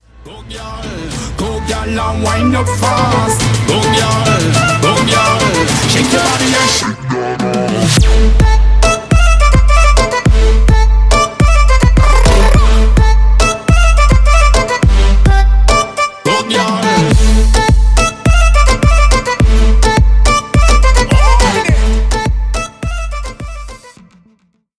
• Качество: 320, Stereo
громкие
dance
Electronic
EDM
Trap
Moombahton
Bass